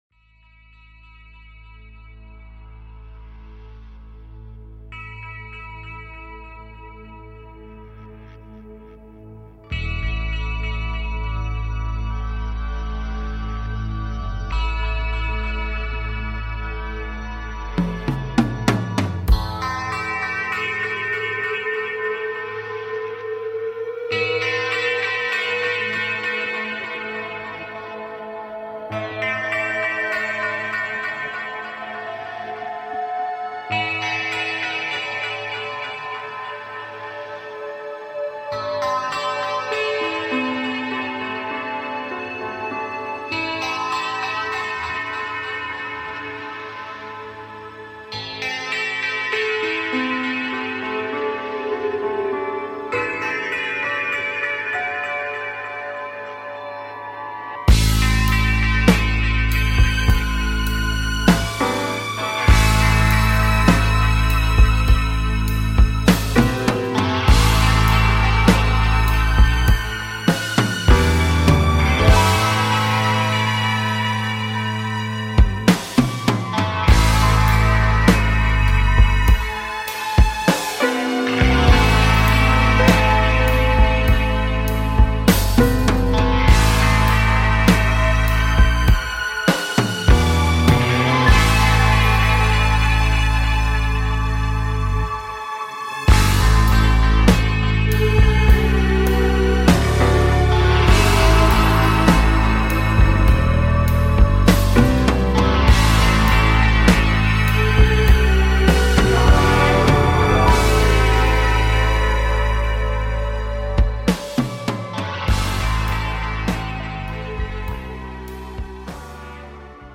Talk Show Episode
Show on Homesteading and taking caller questions